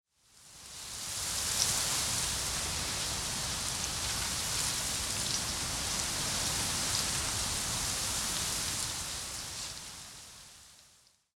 windtree_9.ogg